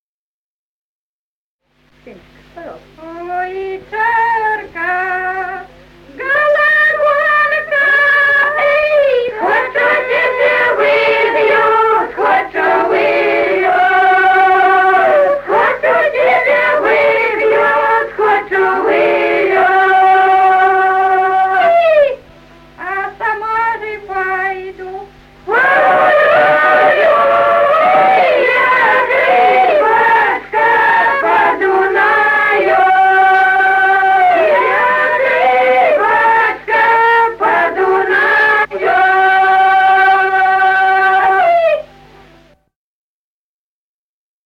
Народные песни Стародубского района «Ой, чарка-гологонка», застольная «банкетная» песня.
с. Курковичи.